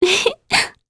Talisha-Vox_Happy1_kr.wav